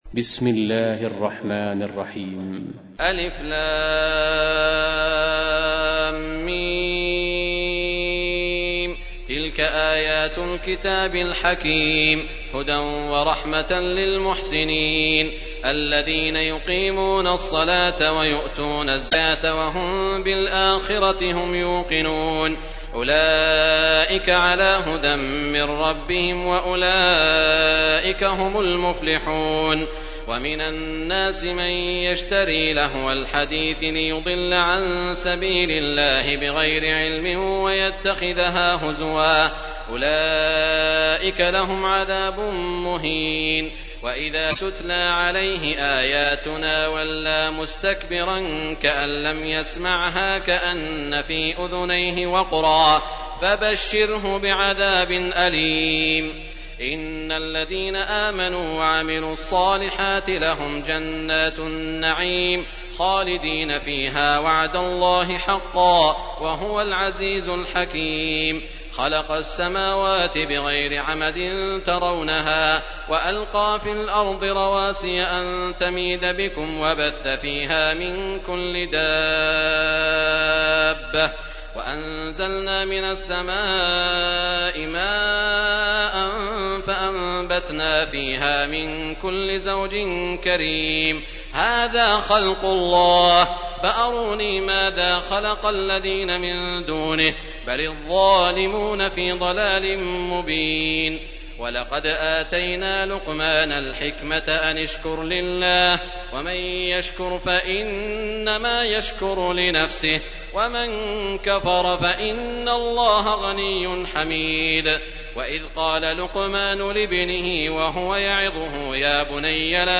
تحميل : 31. سورة لقمان / القارئ سعود الشريم / القرآن الكريم / موقع يا حسين